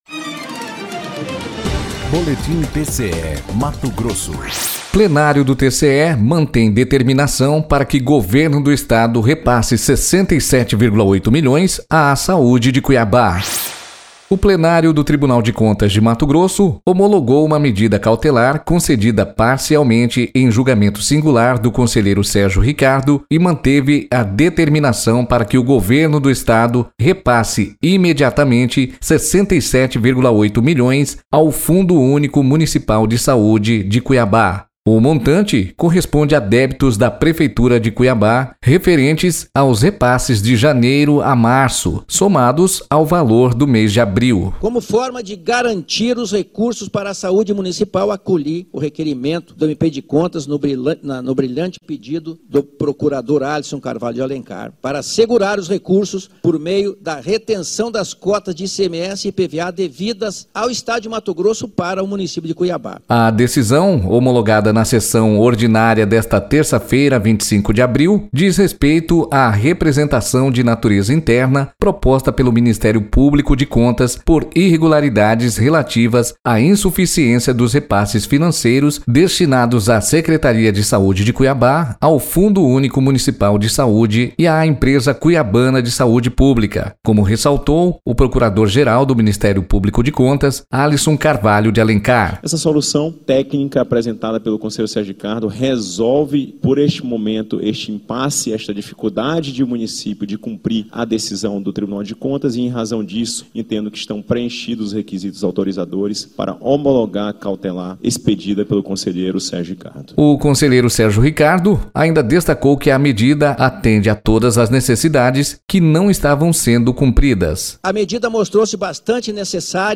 Sonora: Sérgio Ricardo – conselheiro do TCE-MT
Sonora: Alisson Carvalho de Alencar - procurador-geral do MPC-MT